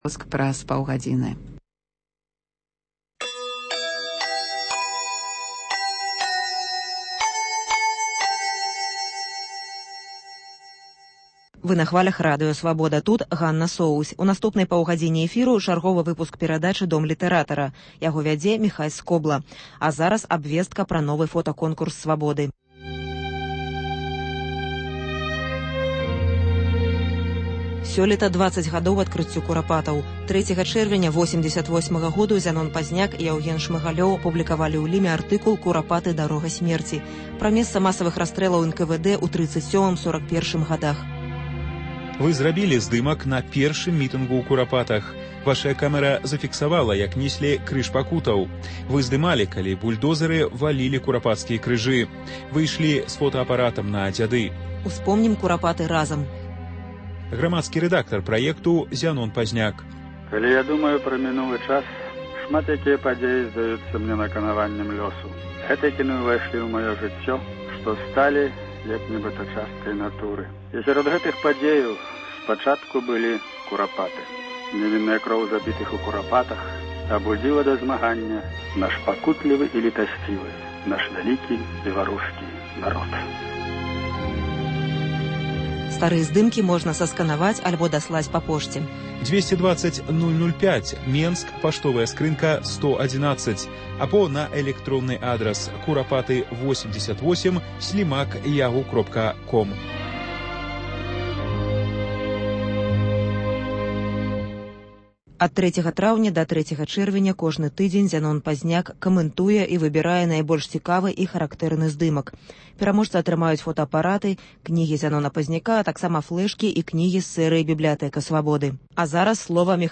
Літаратурны агляд